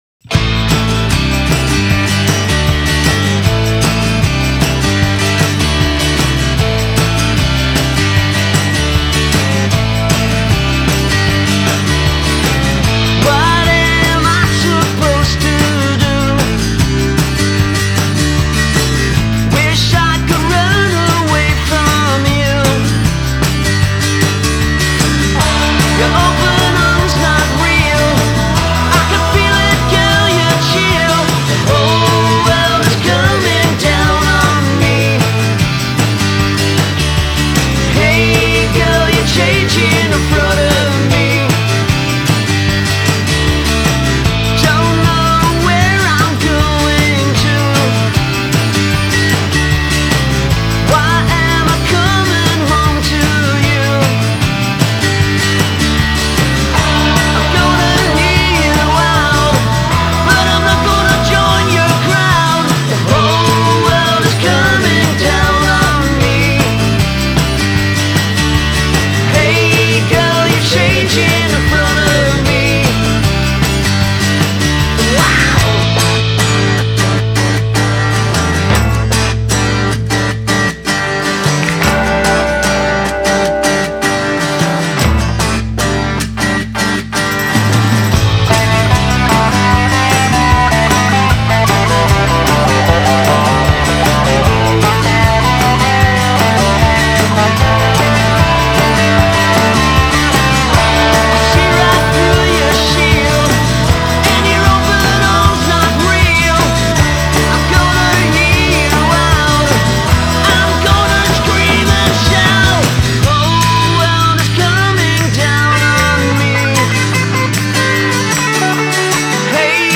and a nice quasi-sitar guitar bit at the end.